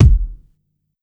• Big Kick F Key 31.wav
Royality free bass drum single shot tuned to the F note. Loudest frequency: 182Hz
big-kick-f-key-31-1lw.wav